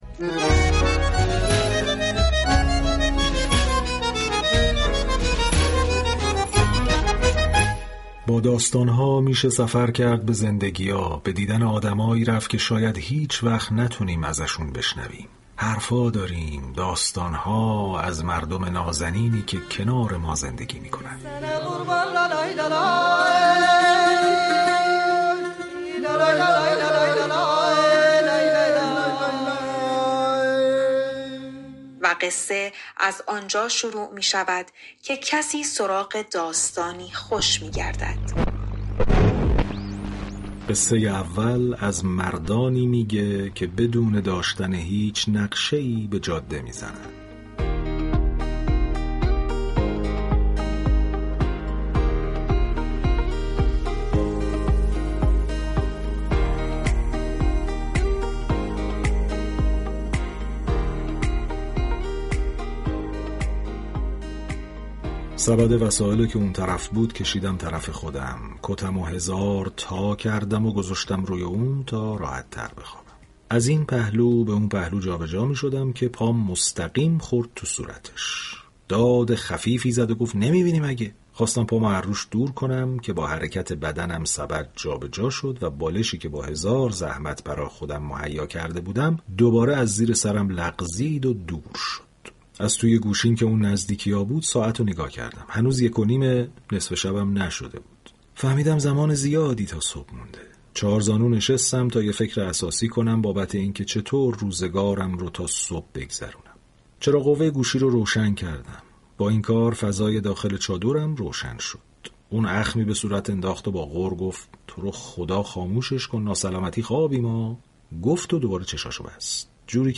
به گزارش روابط عمومی رادیو صبا «این مردم نازنین» عنوان یكی از برنامه‌های این شبكه رادیویی است كه برای مخاطبان به روایت داستان های عامیانه می‌پردازد.
در ادامه بخشی از این داستان ها را می شنویم